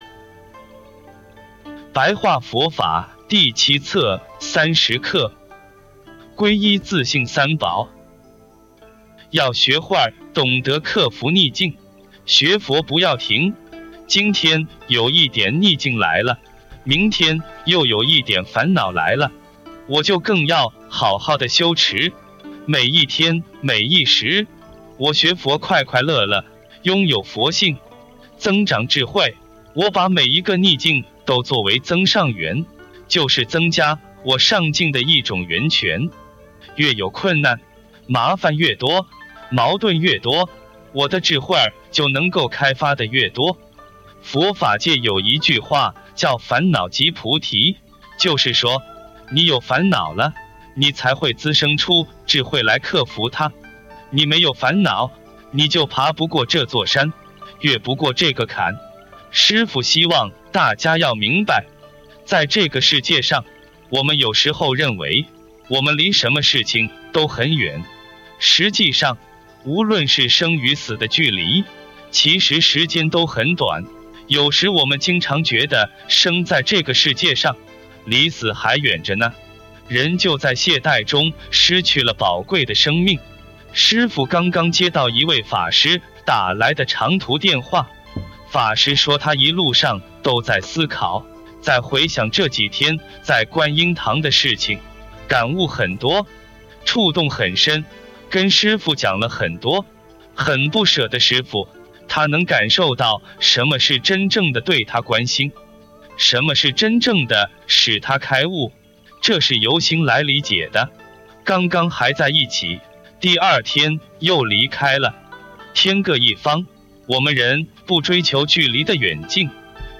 開示